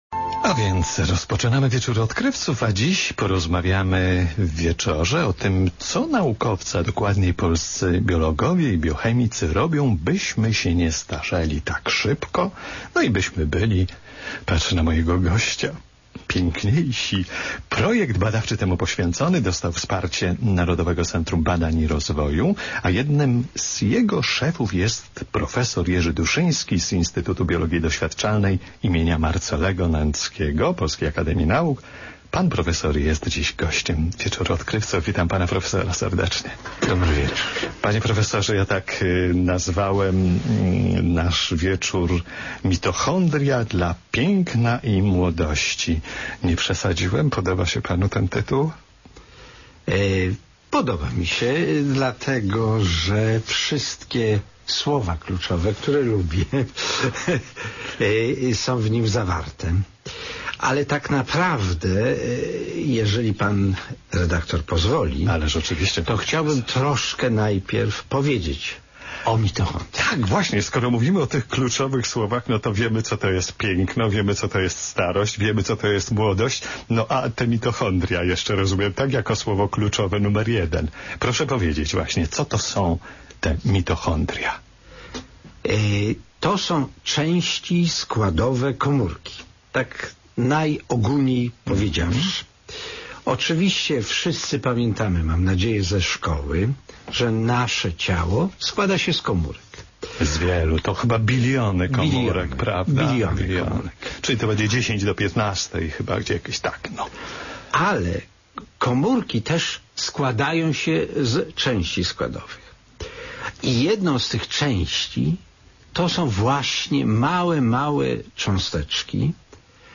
w audycji radiowej z cyklu "Wieczór Odkrywców